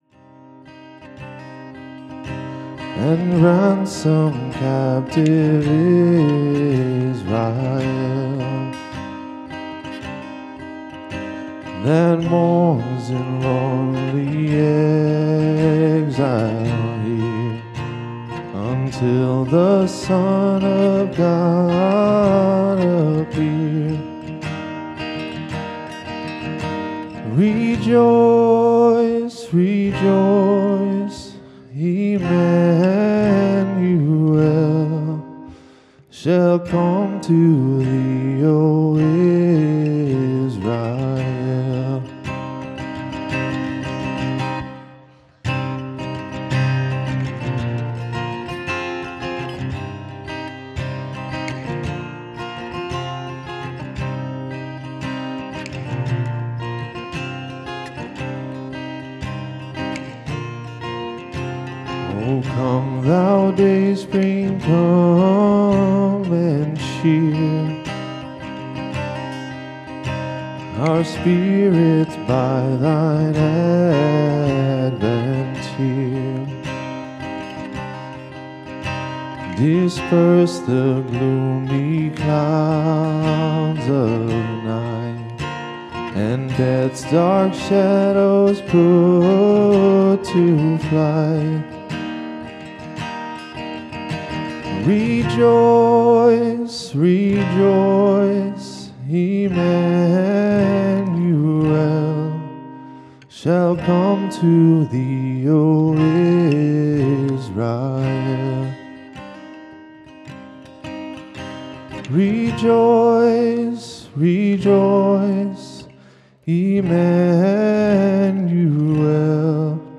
2024 Christmas Eve Midnight Service